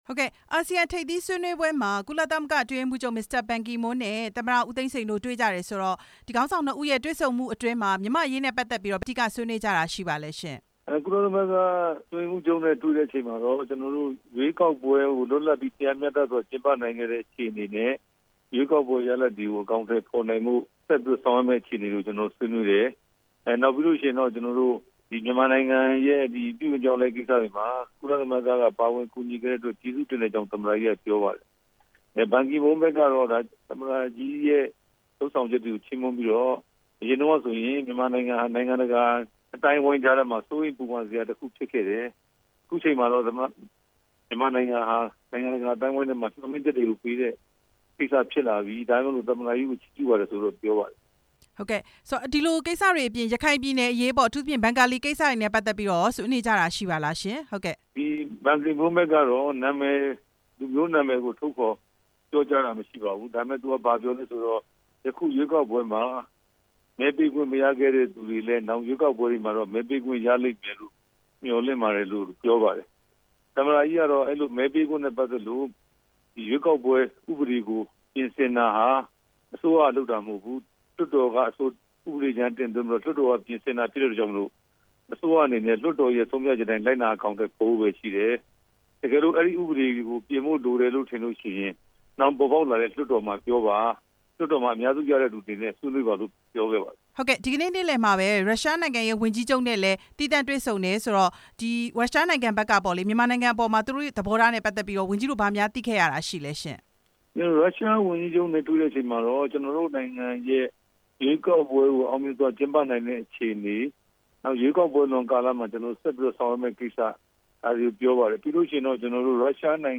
မစ္စတာဘန်ကီမွန်းနဲ့ သမ္မတ ဦးသိန်းစိန် တွေ့ဆုံတဲ့အကြောင်း မေးမြန်းချက်